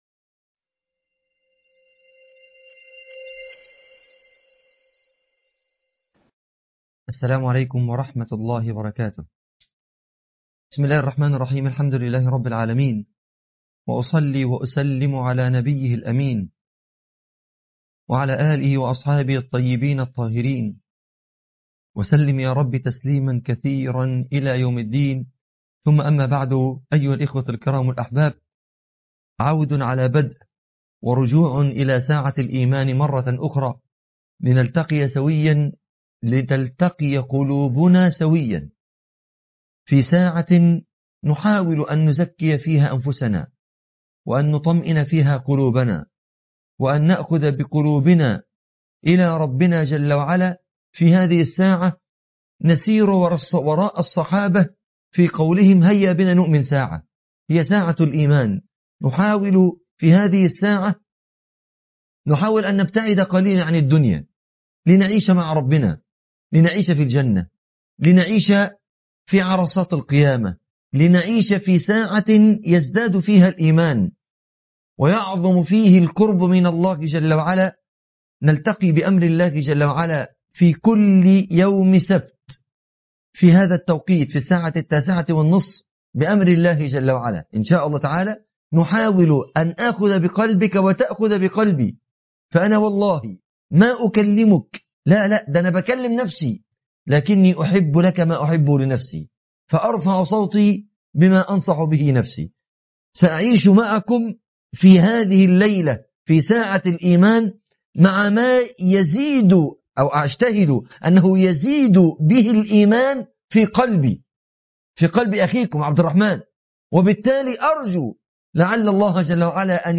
محاضرة 4 || ساعة الإيمان